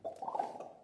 大量的记录根特" 009年的手指弹出，从右到左T2
描述：声音是在比利时根特的大规模人民录音处录制的。
一切都是由4个麦克风记录，并直接混合成立体声进行录音。每个人都用他们的手指在嘴里发出啪啪的声音。从一边平移到另一边。